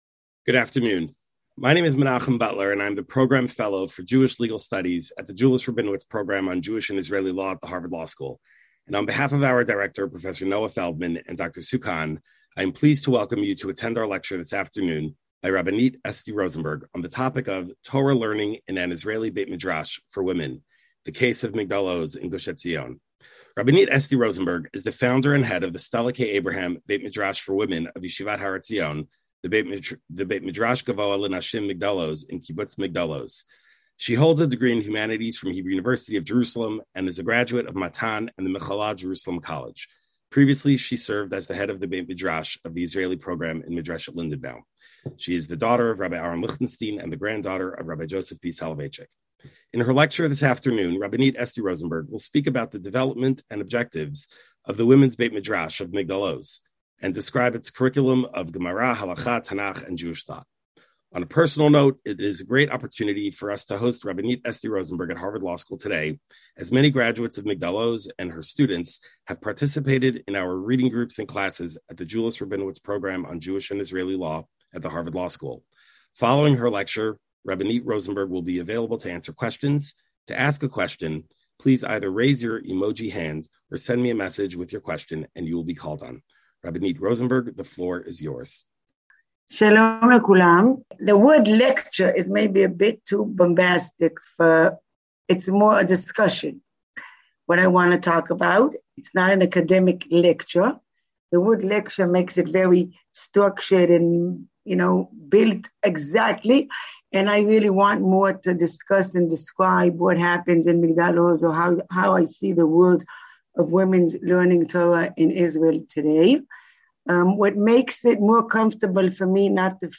"Lecture" at Harvard Law School given over zoom.